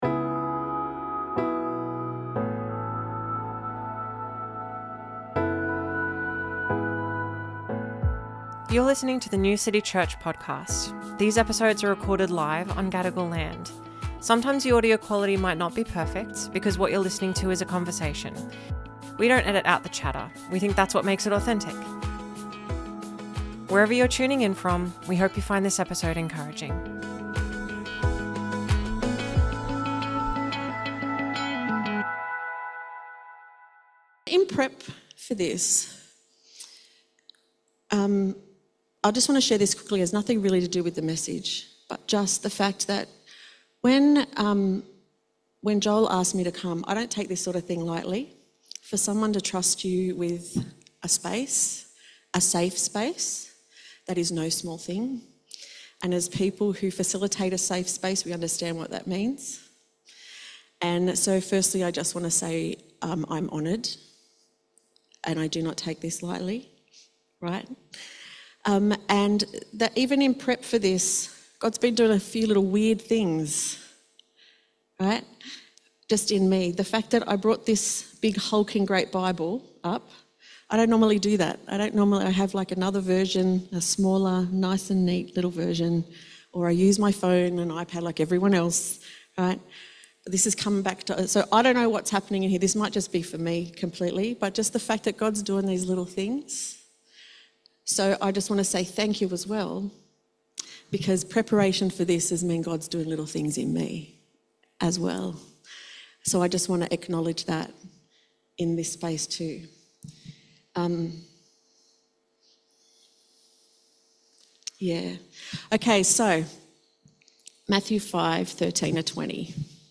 Sermons | New City Church